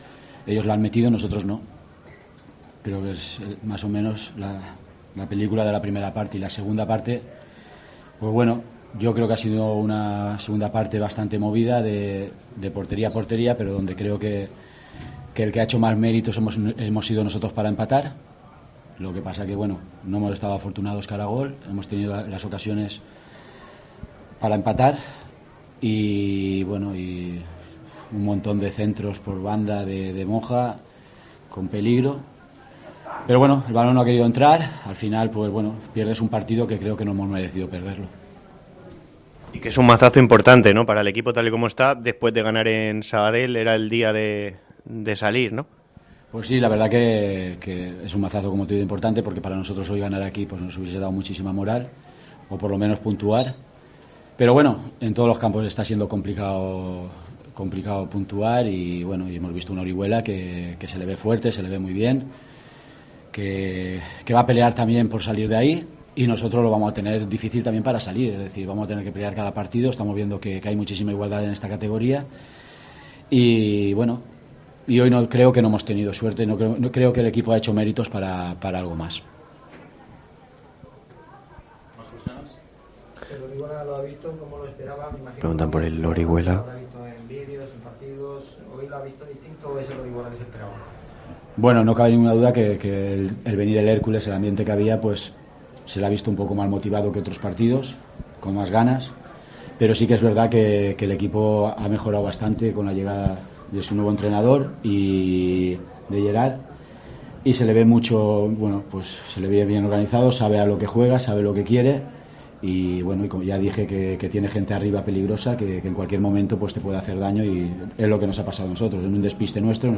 Rueda de prensa